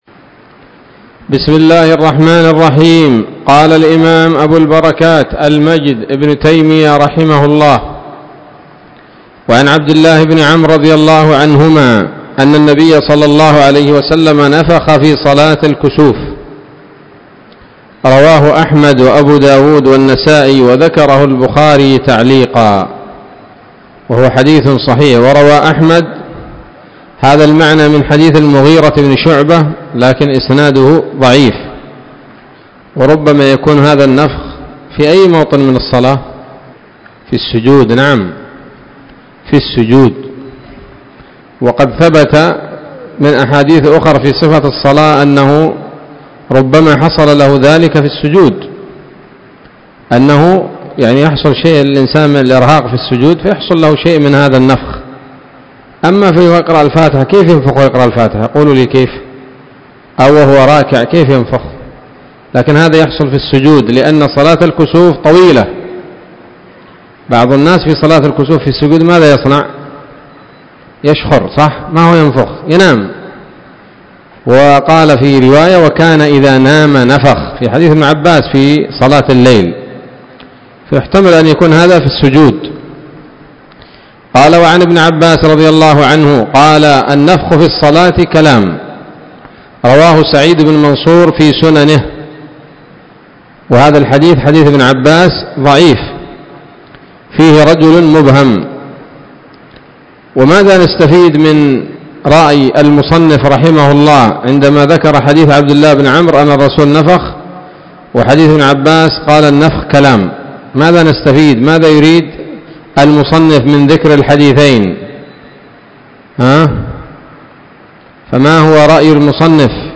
الدرس السادس من أبواب ما يبطل الصلاة وما يكره ويباح فيها من نيل الأوطار